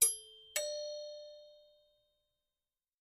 Door Bells; Household Door Bells 4